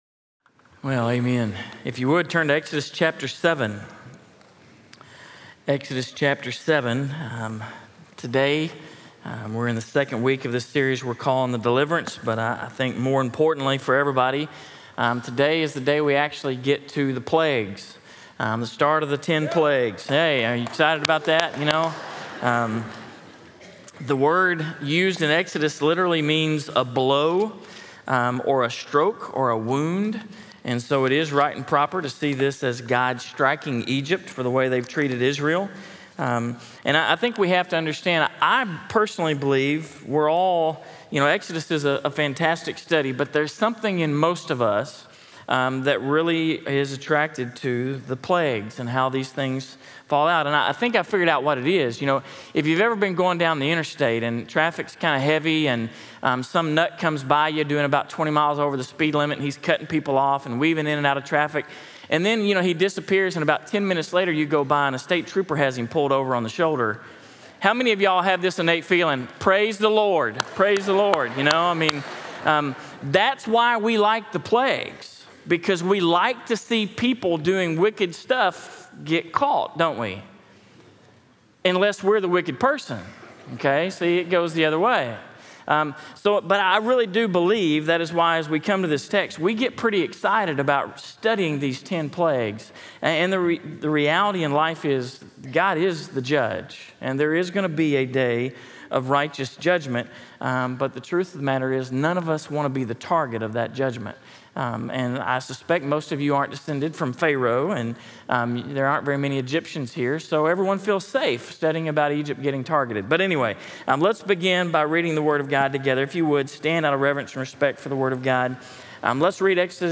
In this message, we move into week two of our sermon series, “The Deliverance,” from Exodus 7-15, and we see the beginning of God’s plagues on Egypt. There’s much to see and discuss, but we should never miss the simplest point: God’s judgment is irresistible and powerful when He chooses to act.